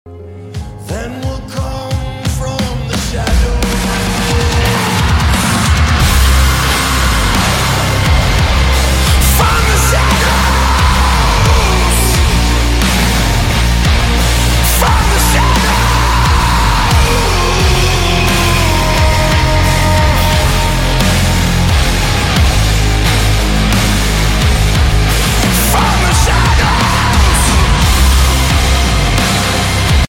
Aussie rock
Strings